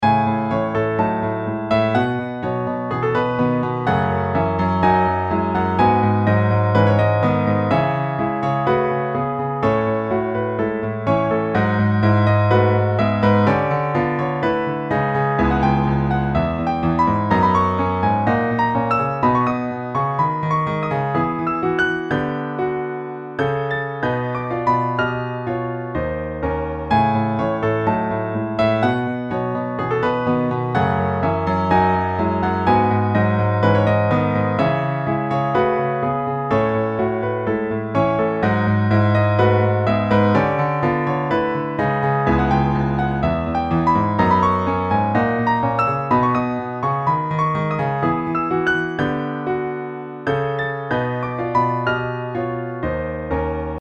So this music is good for Air ships, or fantasy/space adventures. It's pretty short and loops.
Driving and otherworldy.
wonderful_transportation_piano.mp3